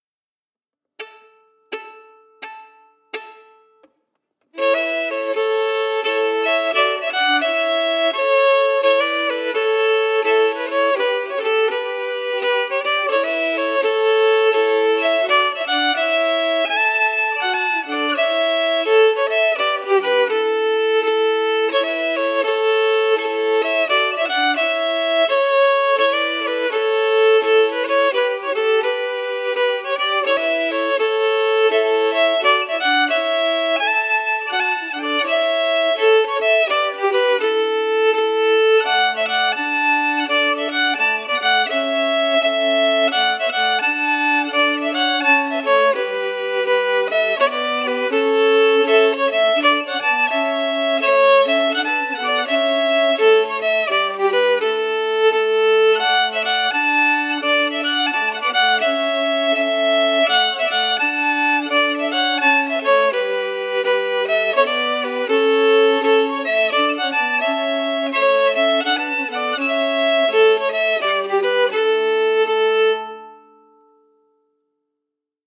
Key: A Mixolydian
Form: Retreat March (9/8 time)
Melody emphasis